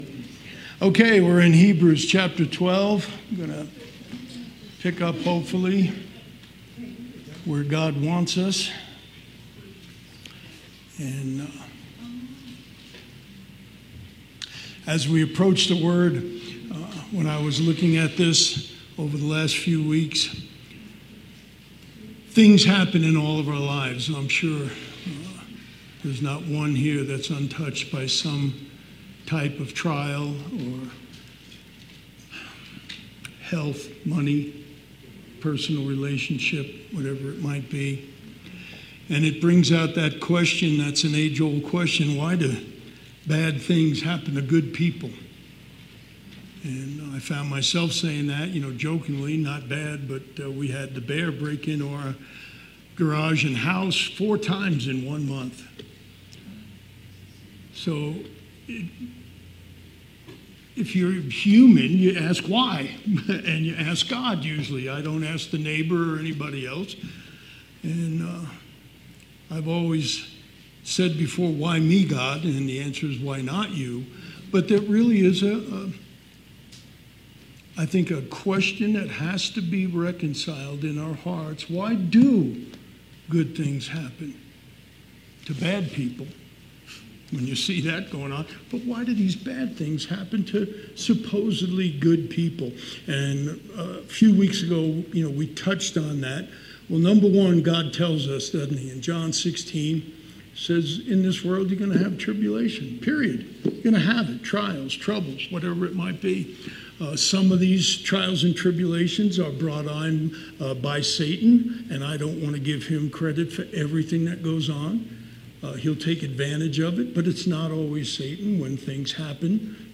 September 10th, 2023 Sermon